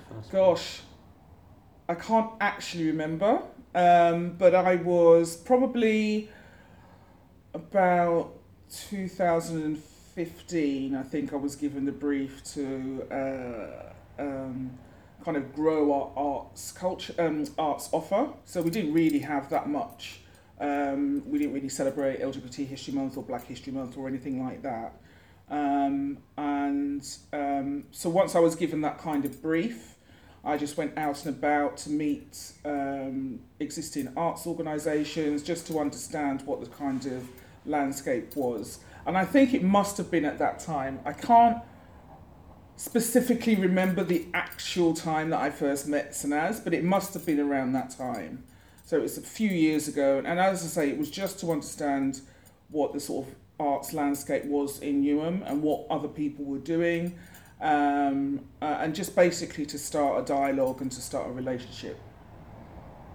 INT – Interviewer